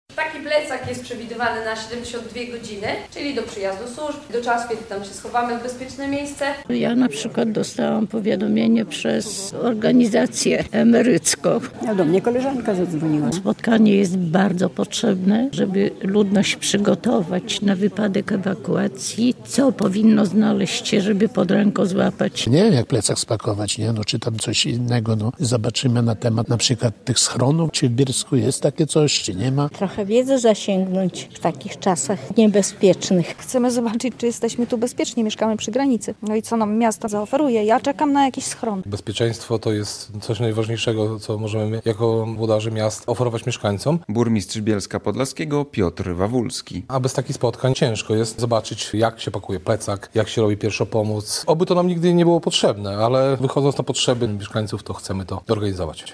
Radio Białystok | Wiadomości | Wiadomości - Jak reagować w sytuacjach kryzysowych - szkolili się bielszczanie